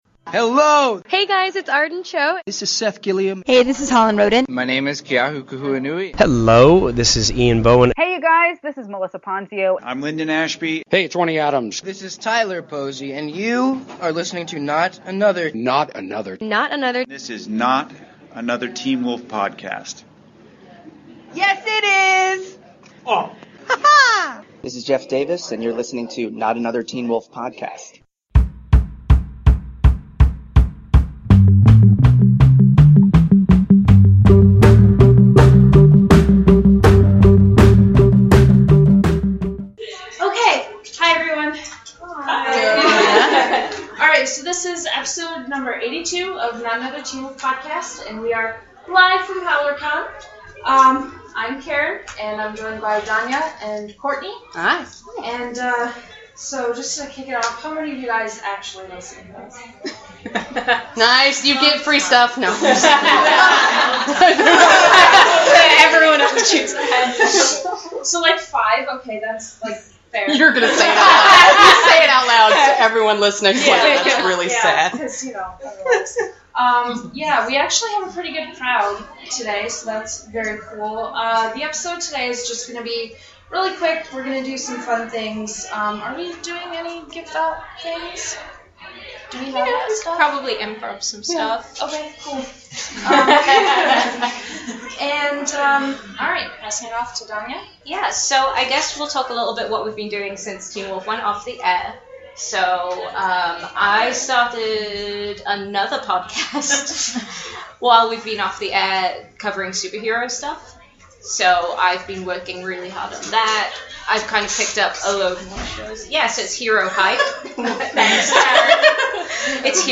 Episode #82 - Live from Howler Con